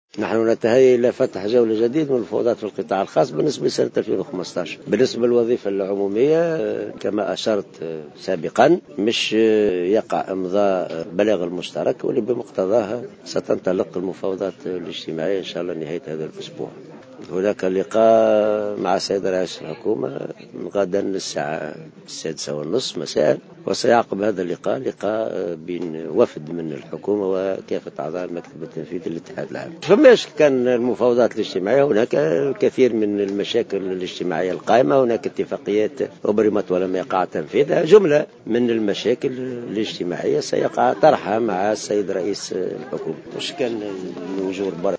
صرح حسين العباسي، الأمين العام للاتحاد العام التونسي للشغل لمراسل الجوهرة أف أم اليوم الاثنين أن المفاوضات الاجتماعية في سنة 2015 لن تقتصر على القطاع العام بل ستشمل كذلك القطاع الخاص.